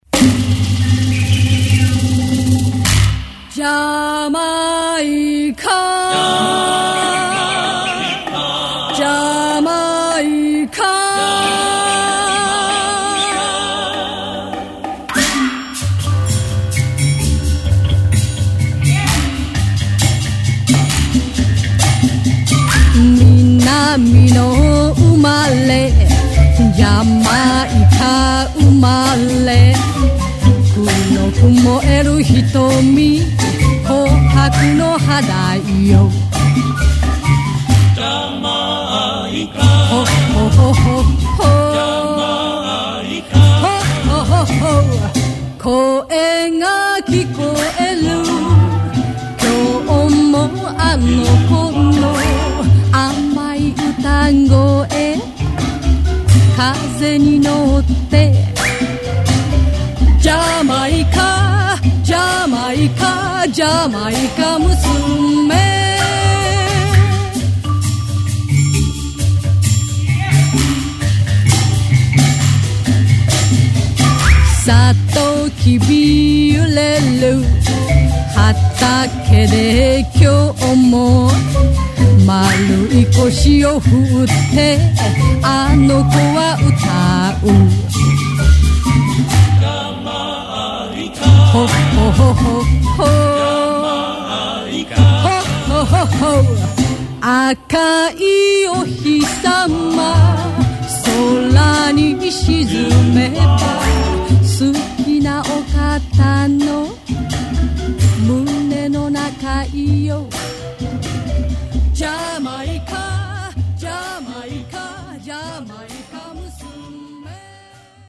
それは、昭和歌謡史の片隅で密かに灯り続けた“最後のカリプソ”の残響であり、